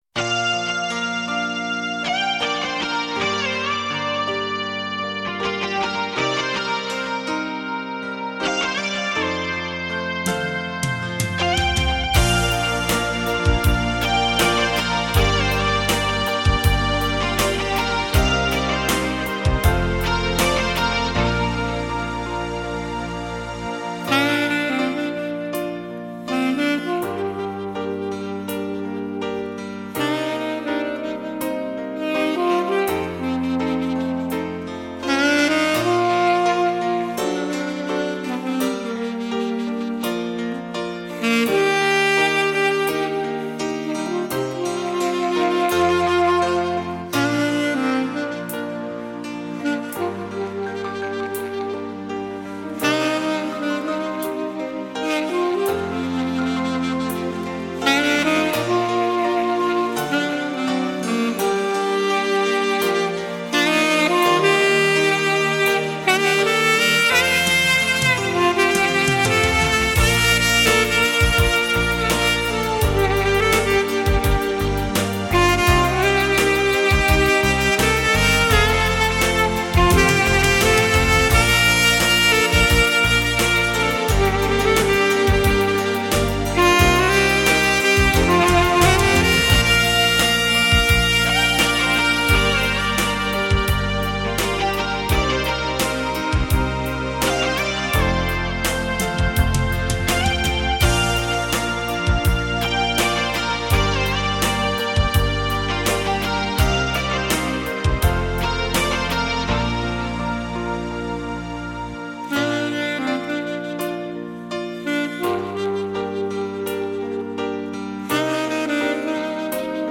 全新数码录音特别版！